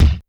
Kick_51.wav